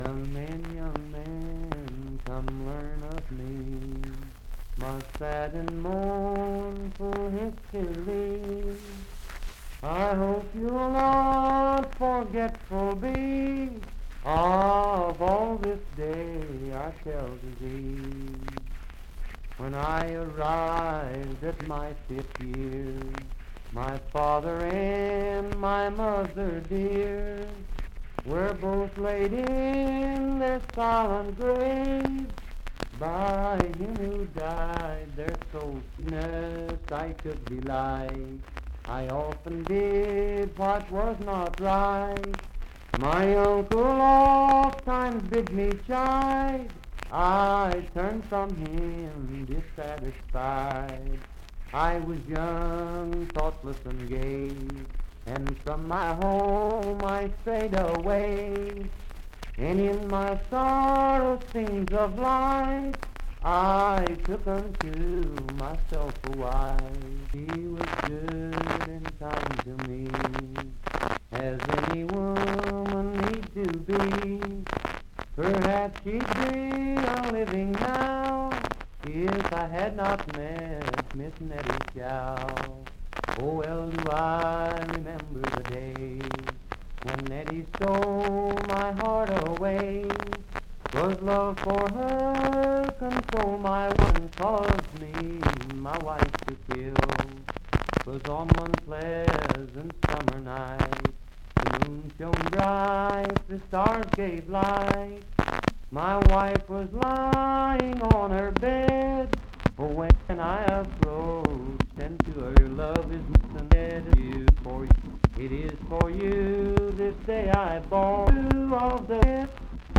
McAfee's Confession - West Virginia Folk Music | WVU Libraries
Unaccompanied vocal music
Voice (sung)